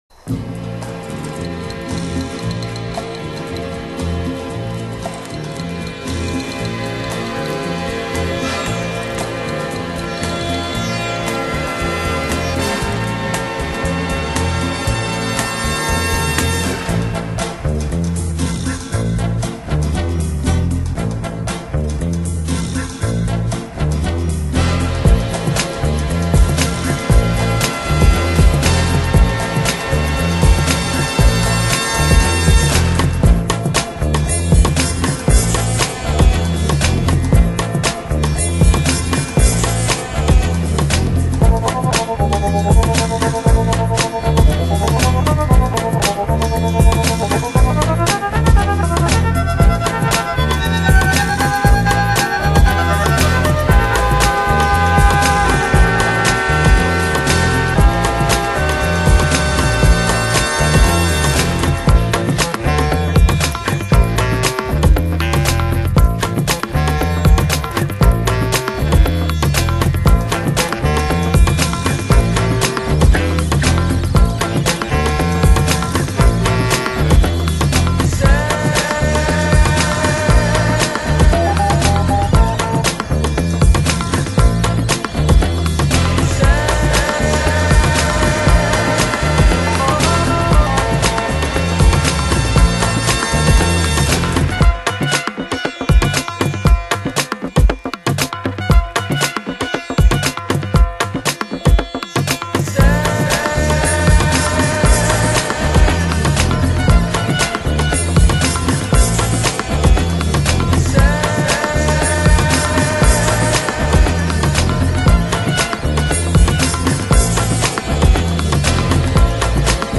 2002-2011 Genre: Lounge | Lo-Fi | Chillout Quality